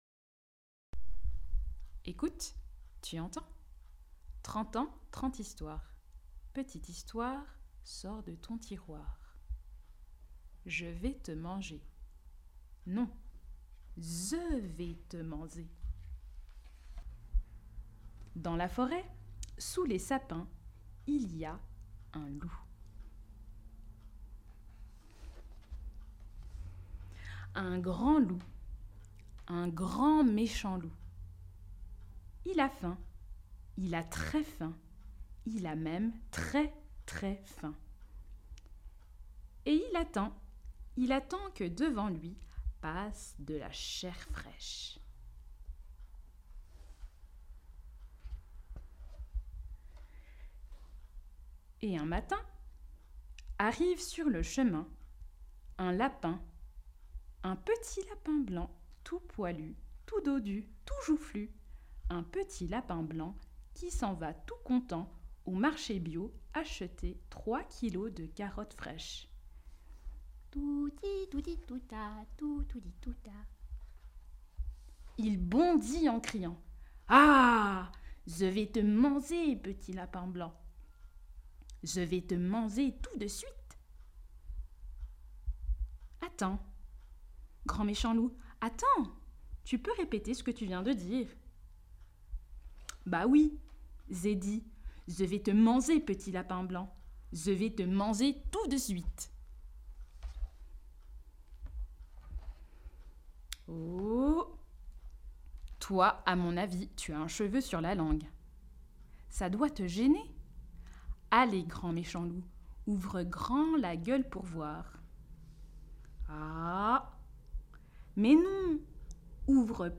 L'histoire gagnante n°3